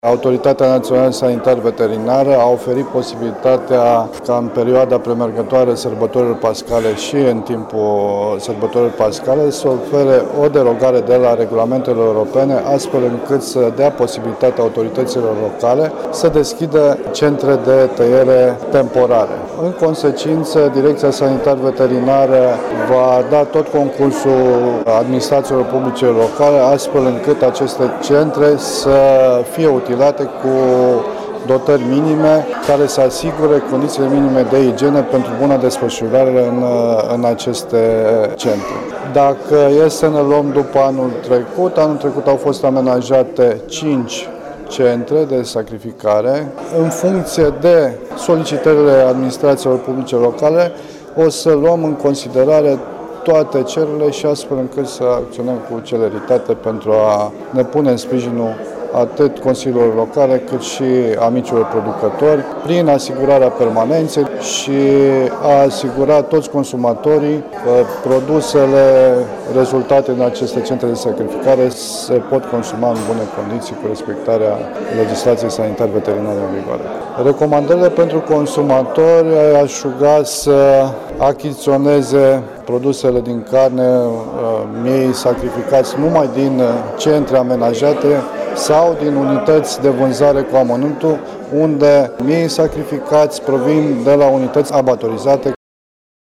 Ascultaţi declaraţia directorului DSVSA Caraş-Severin, dr. Dorin Imbrea: